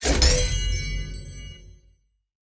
Respawn_Stereo.ogg